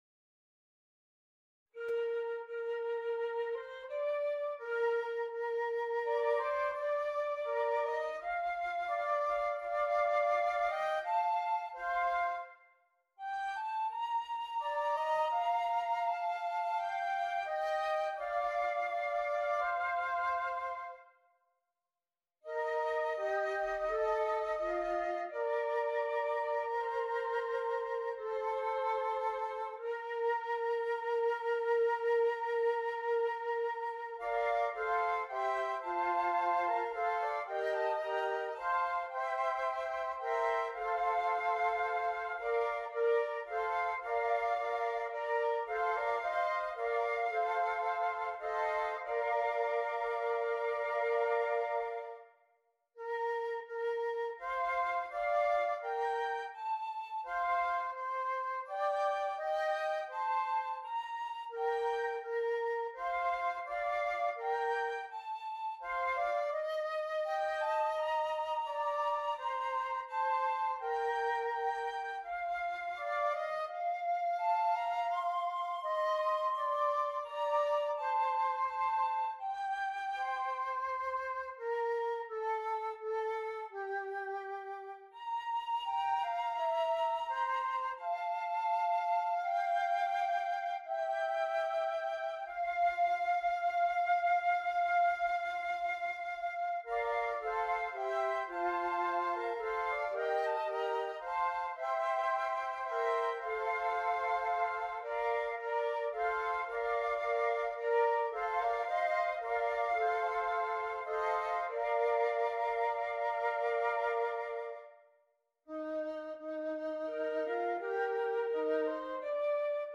4 Flutes